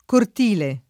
cortile [ kort & le ]